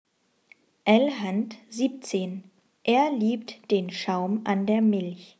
Satz 17 Schnell